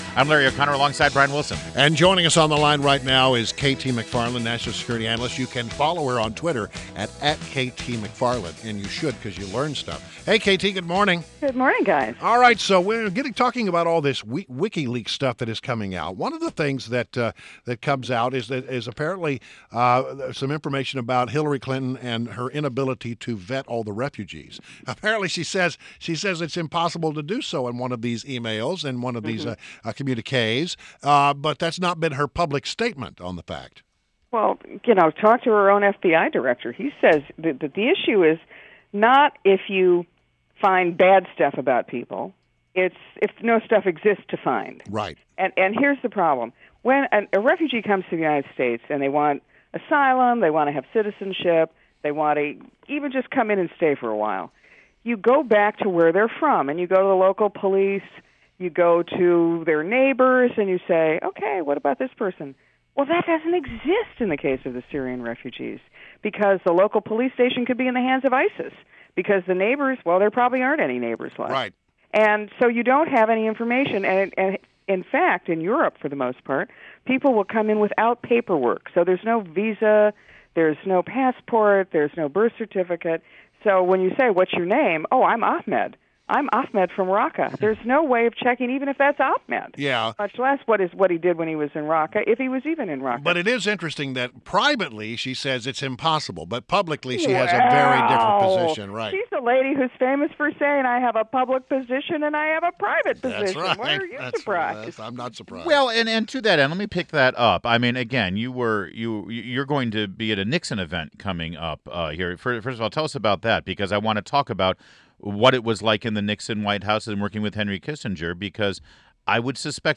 INTERVIEW — KT MCFARLAND – Fox News National Security Analyst